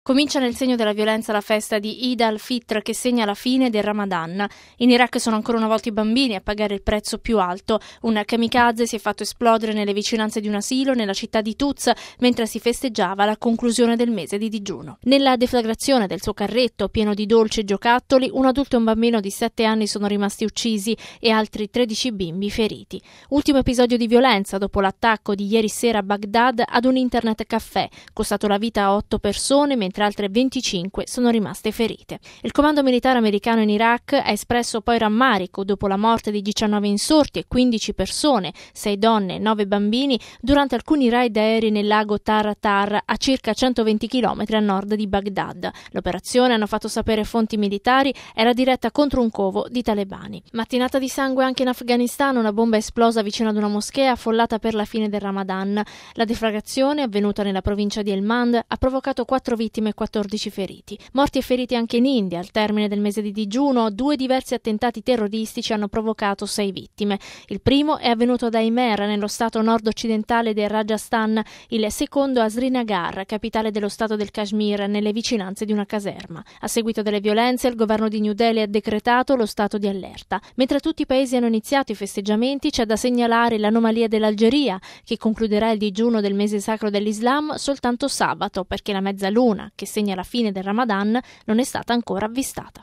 Dall’Iraq, all’Afghanistan fino all’India si registra un’ondata di attentati che hanno provocato numerose vittime. Il nostro servizio: RealAudio